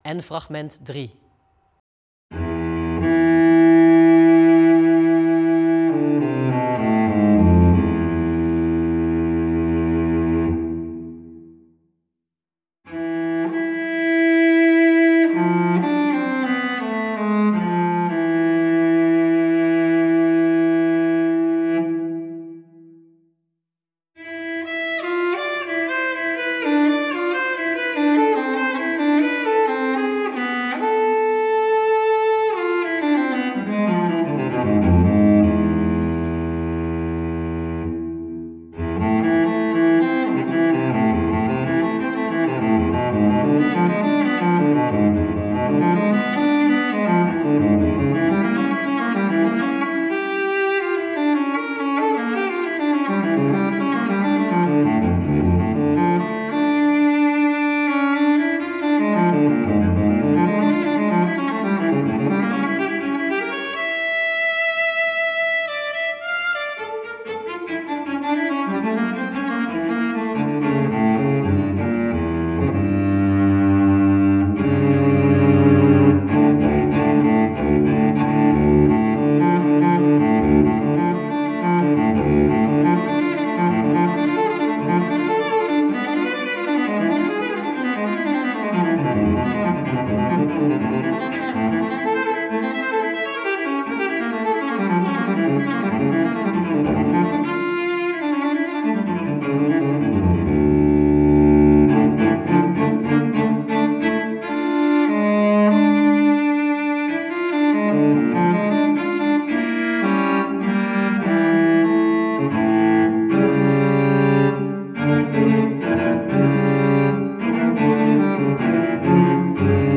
Audio 2.7 t/m 2.9 Verschillende stijlen muziek om op te dansen.
2.9 Alfabet modern klassieke muziek - 2:11 minuten -